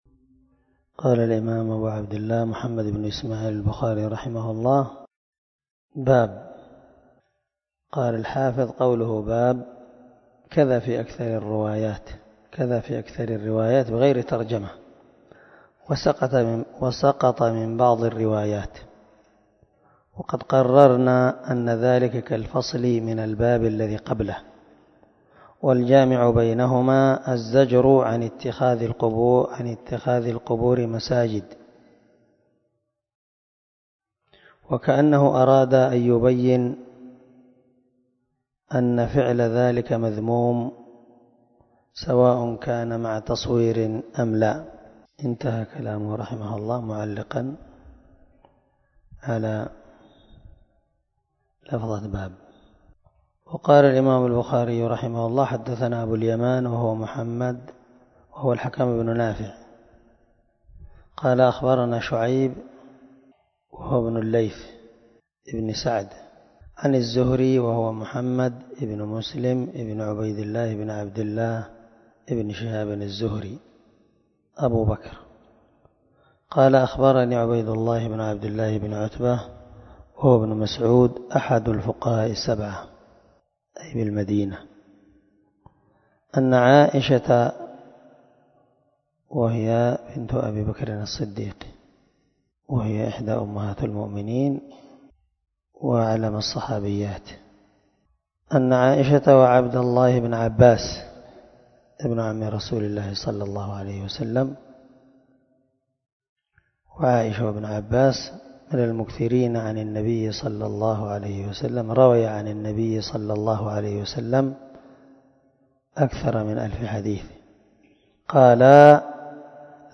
دار الحديث- المَحاوِلة- الصبيحة.